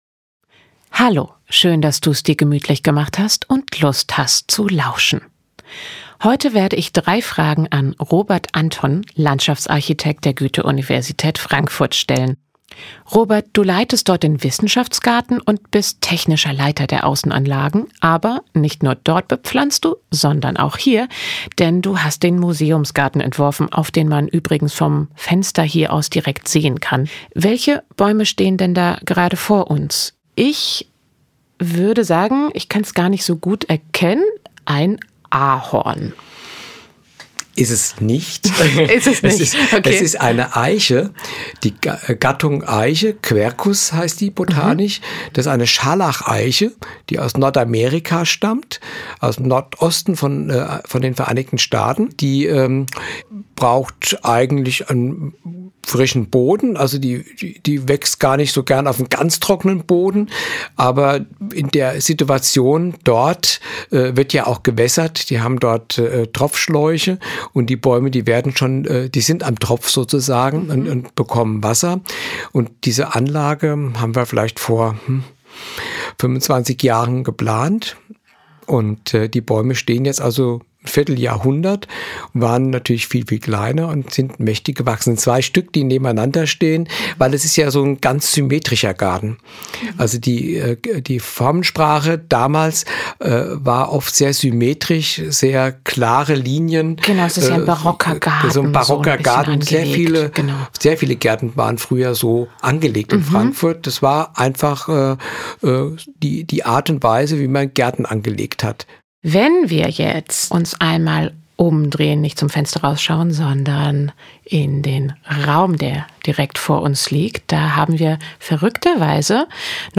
Interview-Podcast mit Dipl.